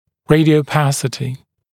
[ˌreɪdɪəu’pæsətɪ][ˌрэйдиоу’пэсэти]рентгеноконтрастность, непроницаемость для рентгеновского излучения, рентгеноконтрастная зона